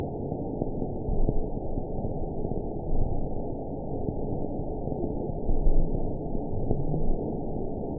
event 920895 date 04/14/24 time 00:22:30 GMT (1 year, 2 months ago) score 7.69 location TSS-AB10 detected by nrw target species NRW annotations +NRW Spectrogram: Frequency (kHz) vs. Time (s) audio not available .wav